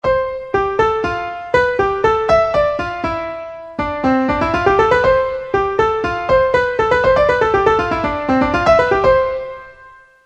Textura monódica. Ejemplo.
piano
mayor
melodía
monodia